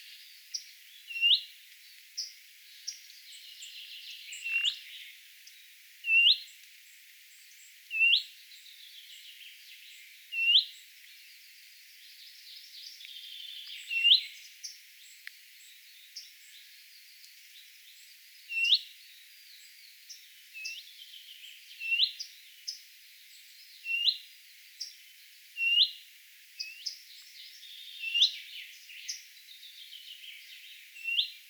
kesän ensimmäinen keltasirkun
lentopoikanen?
taustalla_tietaakseni_ensimmainen_keltasirkun_lentopoikanen_kerjaa.mp3